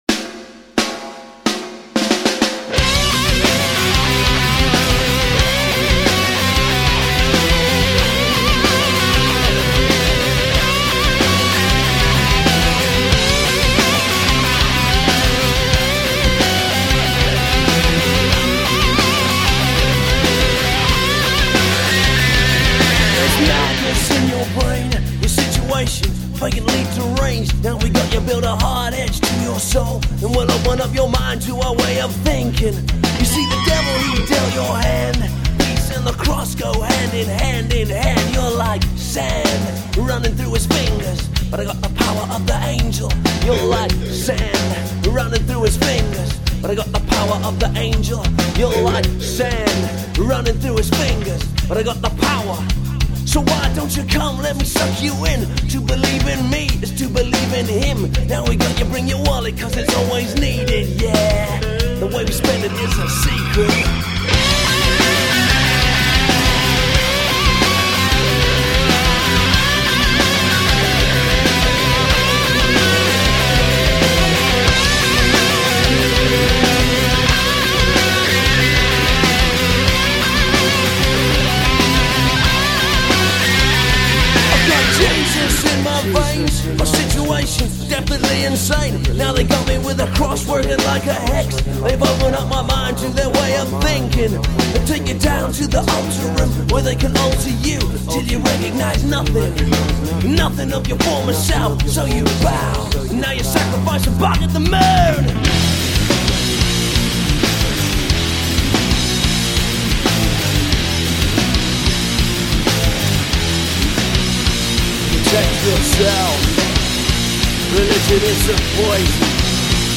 A powerful voice, heartfelt lyrics and tight tunes